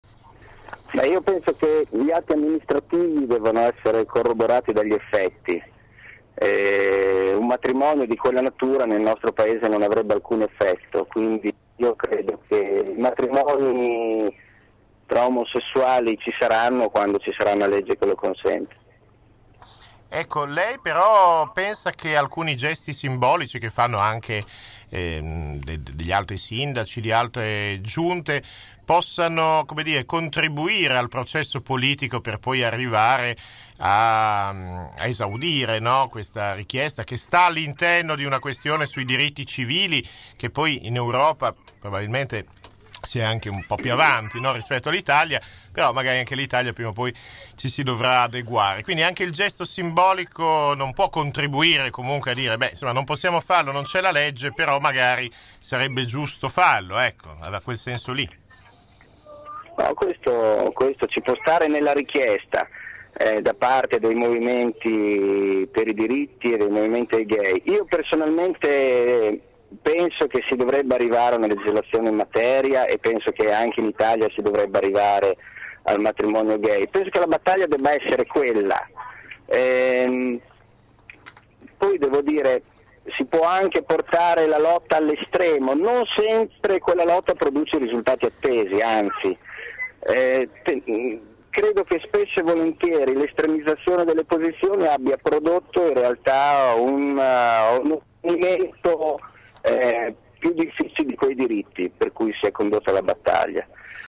Microfono aperto stamattina in radio con il  Vicesindaco Claudio Merighi rimasto vittima, come altre migliaia di bolognesi, del traffico in tilt.